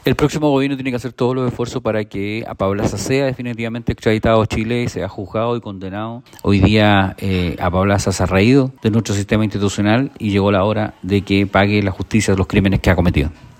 En la misma línea, el jefe de bancada de Renovación Nacional (RN), Frank Sauerbaum, afirmó que el próximo Gobierno debe hacer todos los esfuerzos para que la extradición se concrete y para que Apablaza sea juzgado y condenado en Chile.